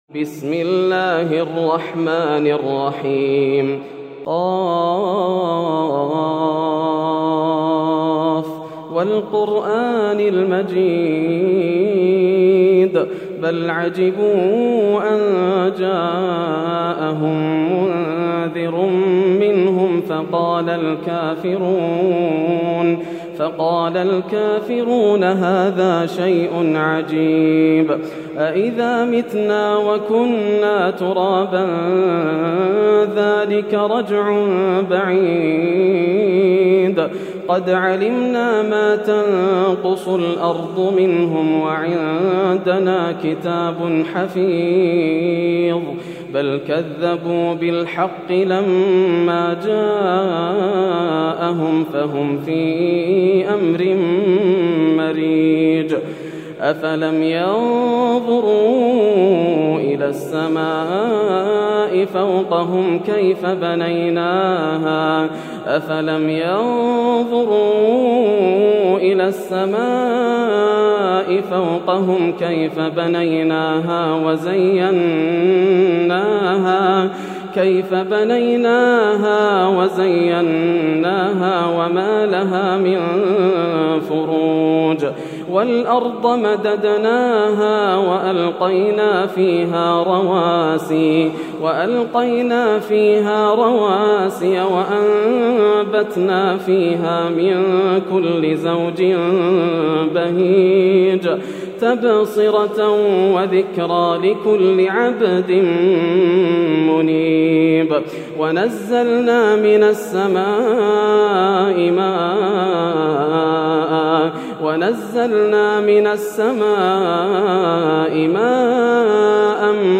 سورة ق > السور المكتملة > رمضان 1431هـ > التراويح - تلاوات ياسر الدوسري